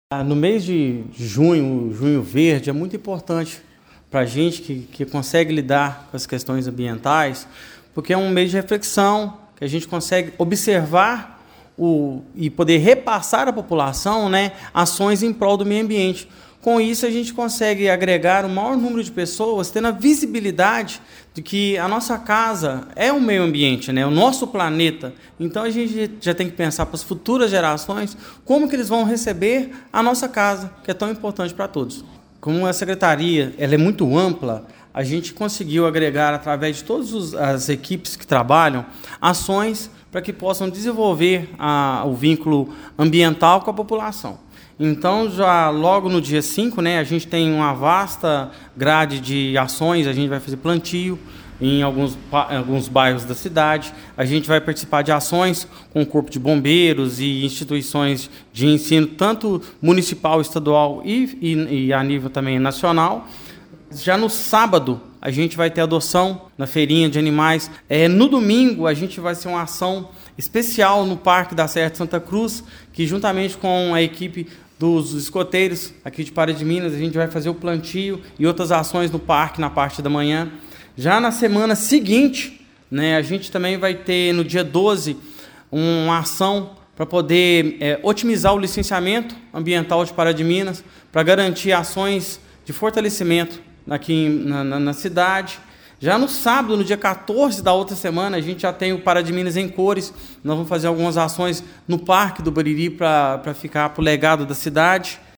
O secretário da pasta, Kenede Antônio dos Reis, destacou que o mês é uma oportunidade essencial para fortalecer a consciência ambiental e envolver a população em práticas sustentáveis. Segundo ele, o Junho Verde permite consolidar projetos e dar maior visibilidade às ações ambientais do município: